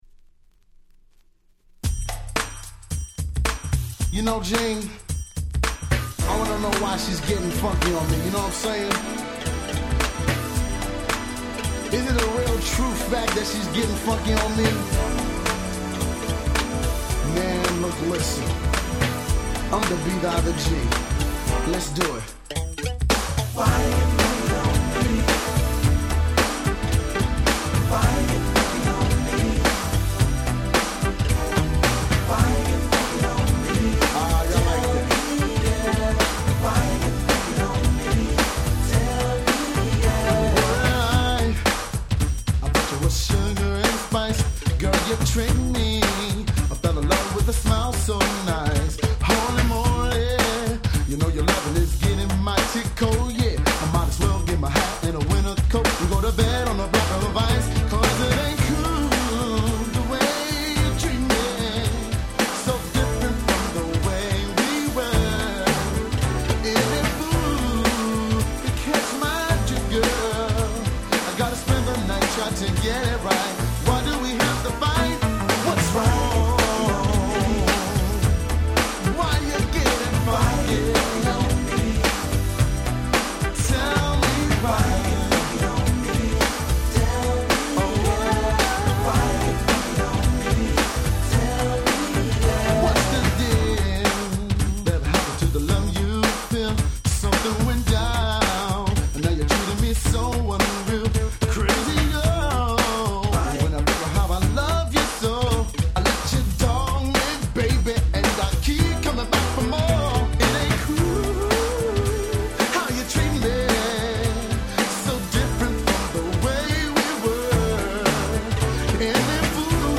90' Nice New Jack Swing !!